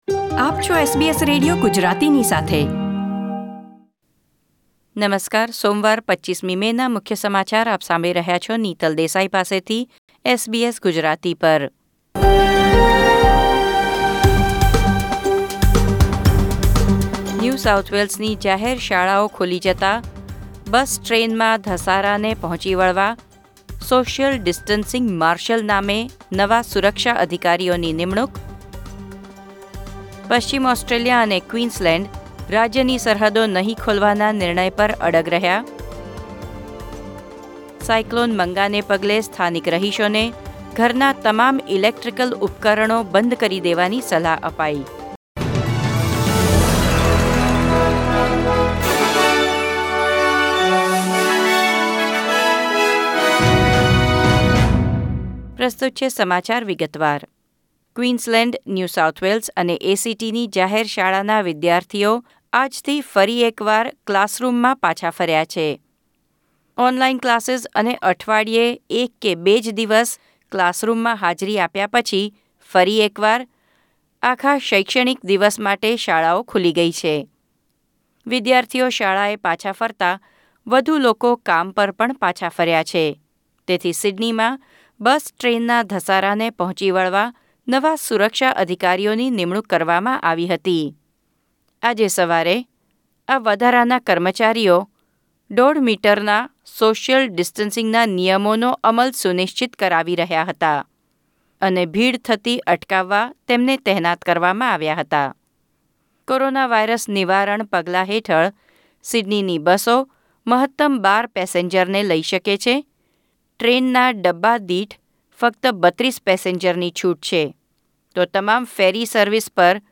SBS Gujarati News Bulletin 25 May 2020